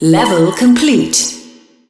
level_complete.wav